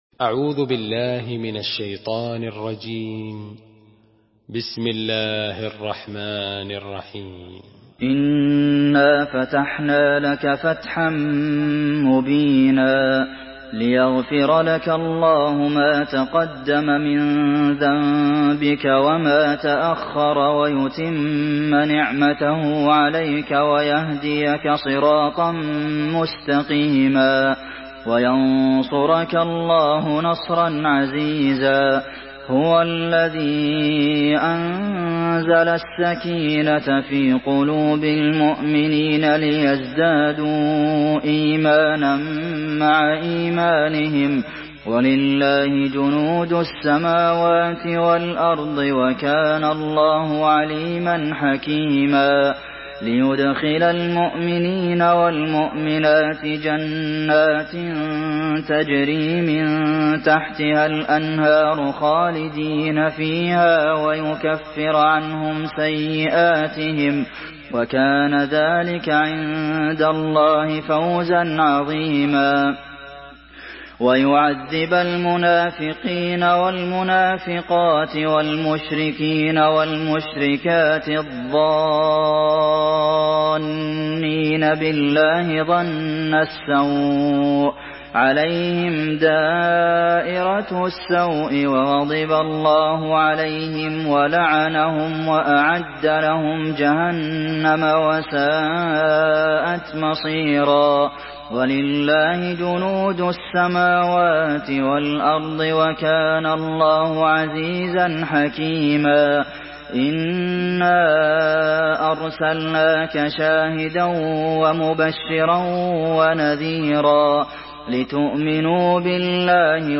Surah الفتح MP3 by عبد المحسن القاسم in حفص عن عاصم narration.
مرتل